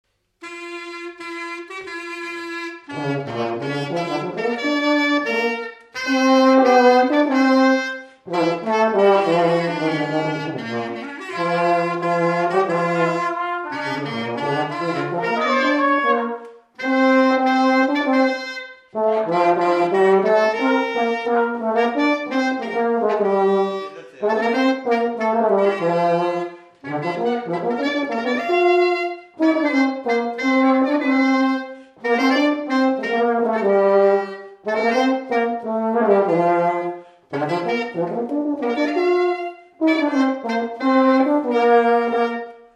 Résumé instrumental
gestuel : à marcher
circonstance : fiançaille, noce
Pièce musicale inédite